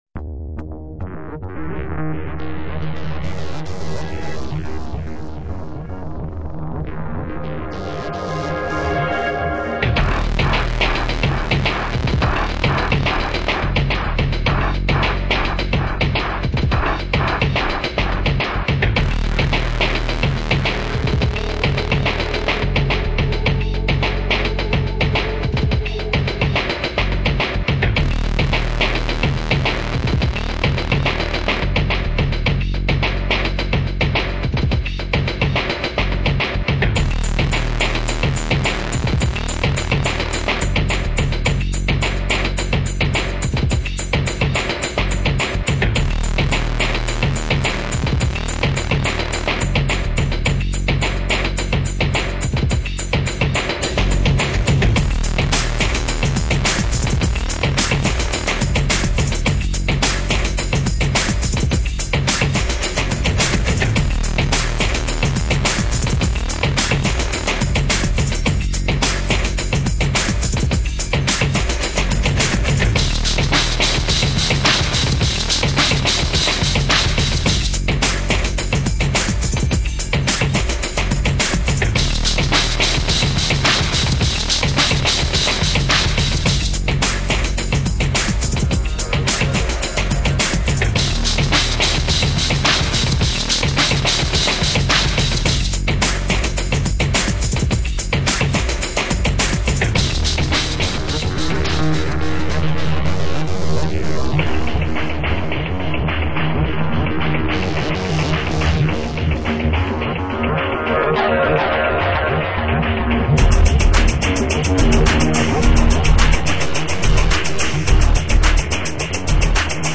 credits music